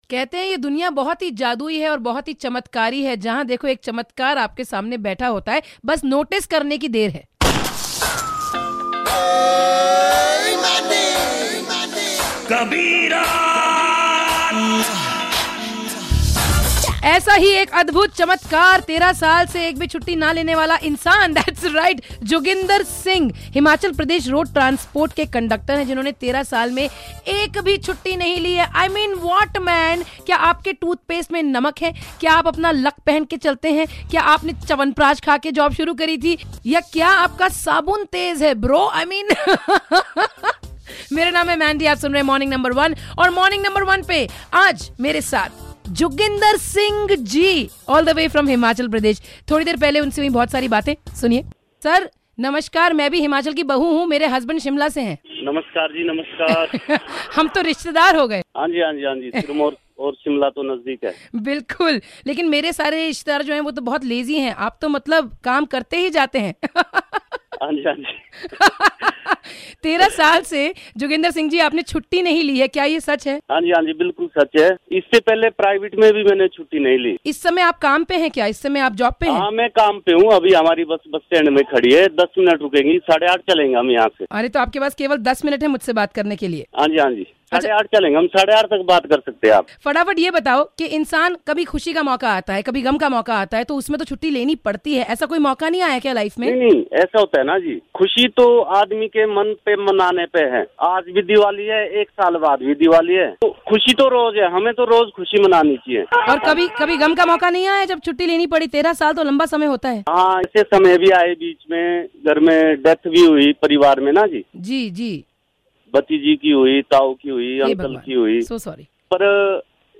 had a fun conversation